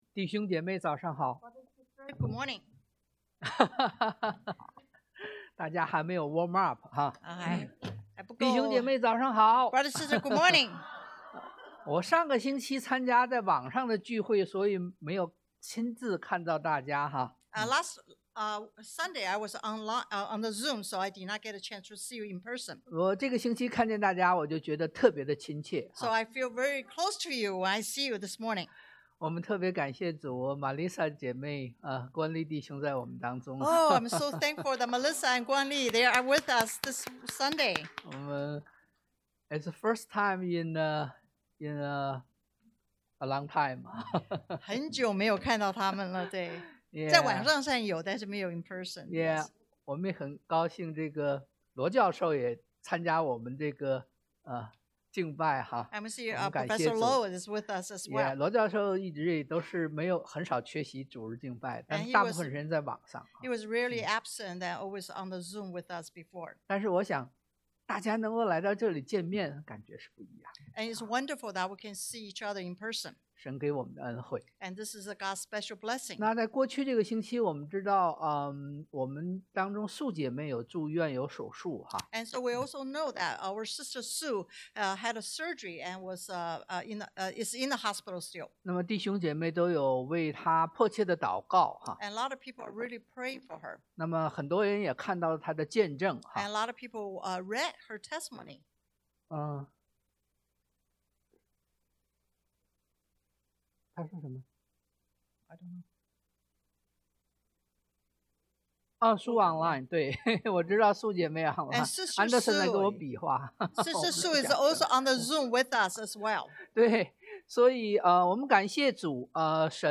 約 John 6:1-15 Service Type: Sunday AM 1.